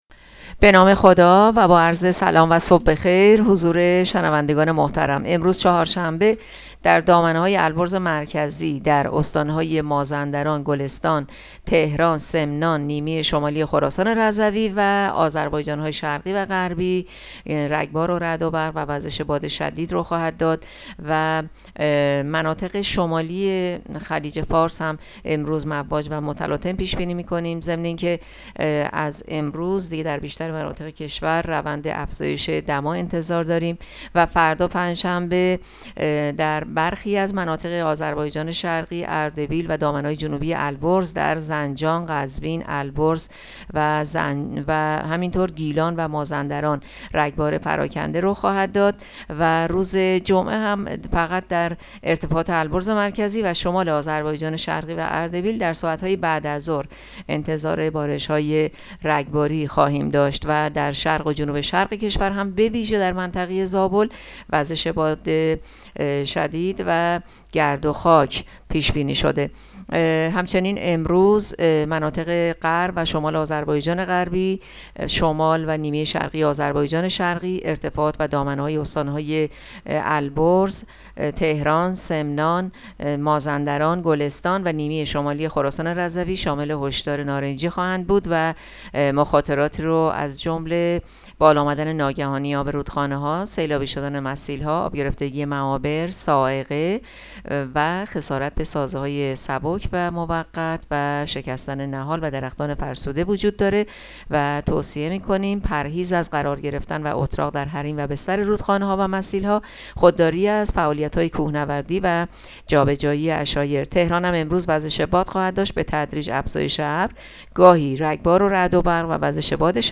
گزارش رادیو اینترنتی پایگاه‌ خبری از آخرین وضعیت آب‌وهوای ۲۱ خرداد؛